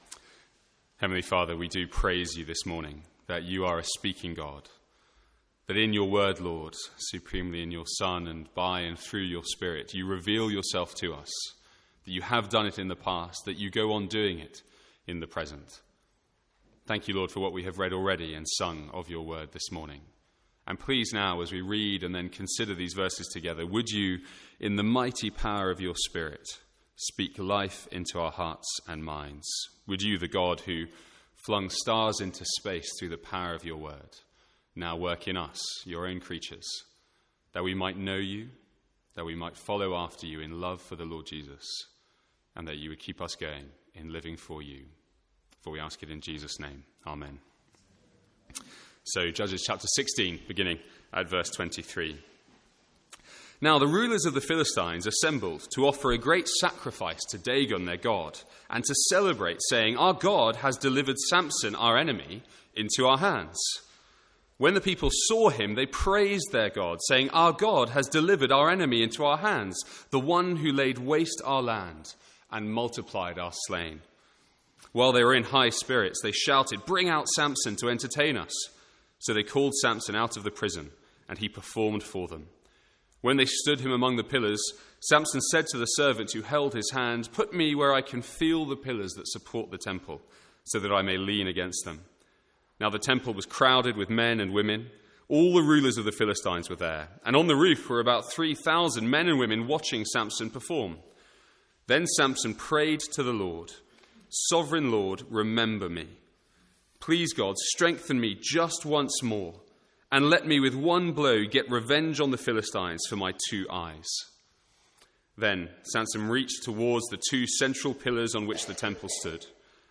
Sermons | St Andrews Free Church
From the Sunday morning series in Judges.